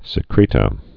(sĭ-krētə)